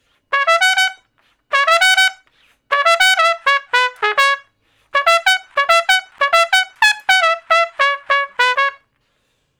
087 Trump Straight (Db) 06.wav